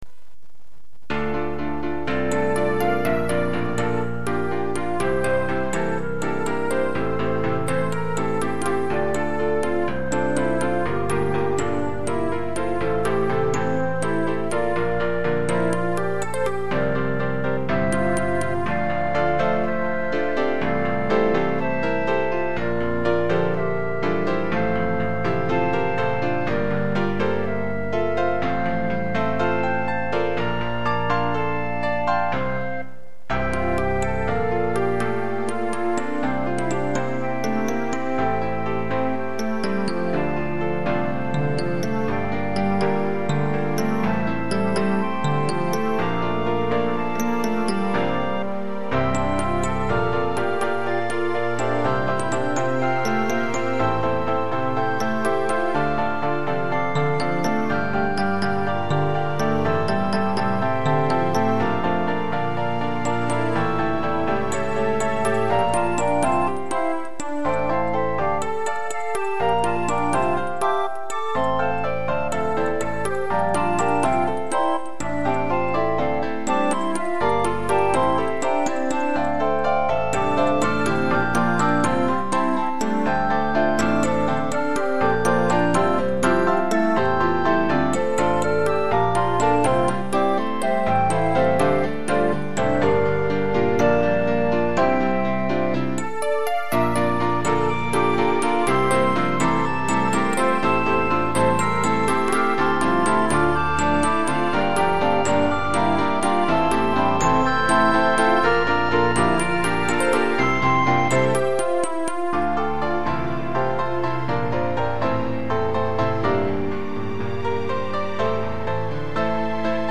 カメレオン 初ボーカロイド・歌モノ作成。
しかも明るい。